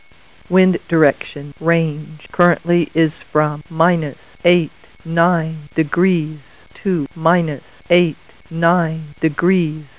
Weather Word connects to a standard telephone line, can run on batteries, and answers the phone with a pleasing female voice
Typical Telephone Answer: